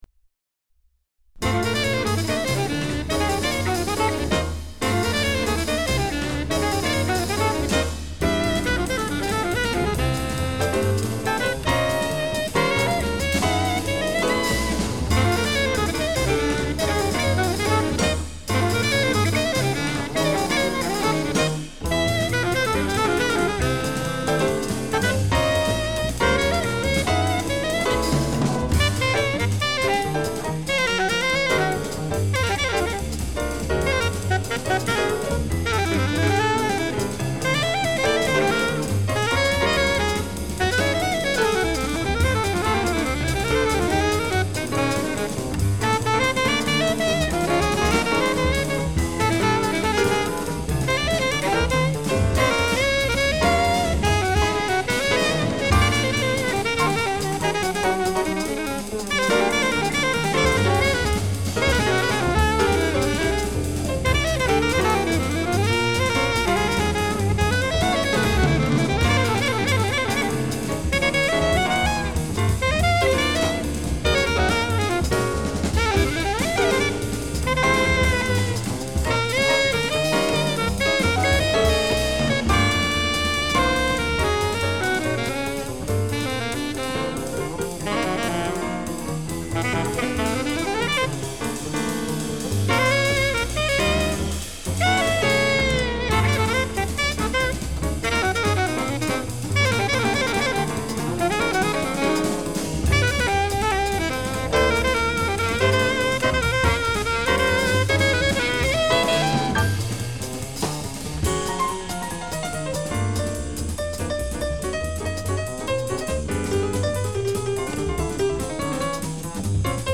Geneva, Aquarius Studio - 1977
sax alto